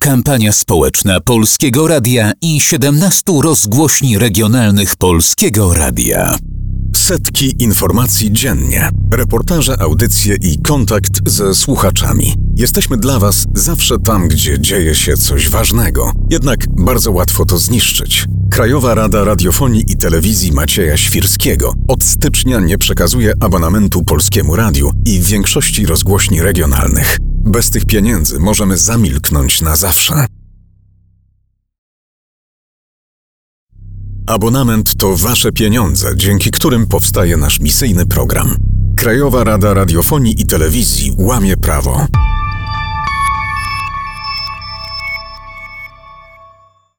Komunikaty specjalne, jednakowe we wszystkich rozgłośniach, są emitowane po serwisach o godz. 8:00, 9:00, 15:00, 16:00.